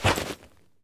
foley_jump_movement_throw_05.wav